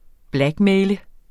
Udtale [ ˈblagˌmεjlə ]